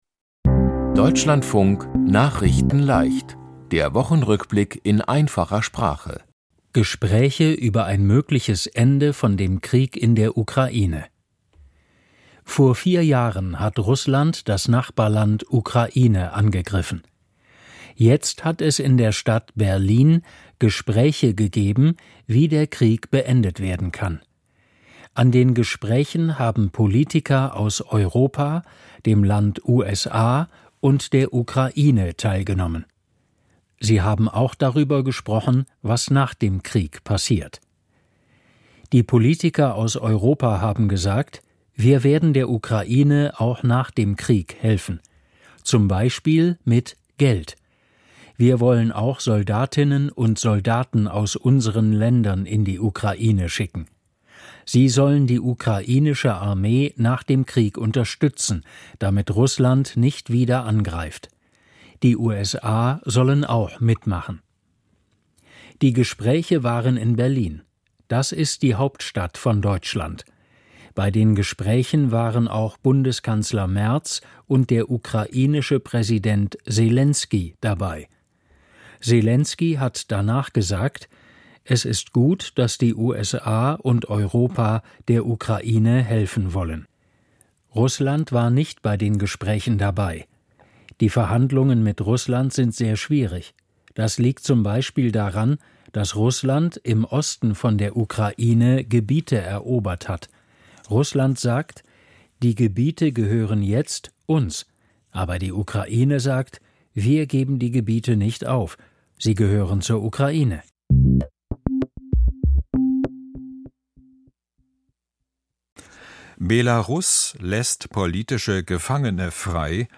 Die Themen diese Woche: Gespräche über ein mögliches Ende von dem Krieg in der Ukraine, Belarus lässt politische Gefangene frei – auch Maria Kolesnikowa, Bundes-Regierung will das Bürger-Geld ändern, Rosa von Praunheim ist gestorben und Deutsche Hand-Spielerinnen gewinnen Silber bei Welt-Meisterschaft. nachrichtenleicht - der Wochenrückblick in einfacher Sprache.